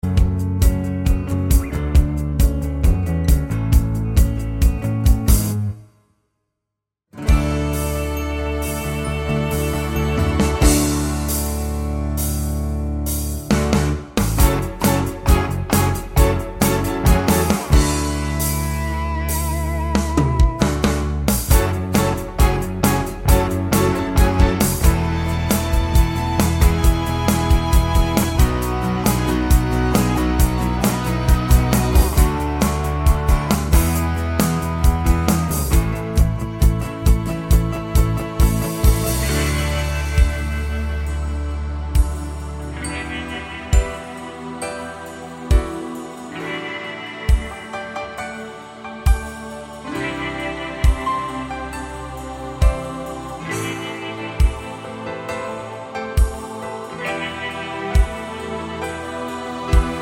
no Backing Vocals Musicals 3:03 Buy £1.50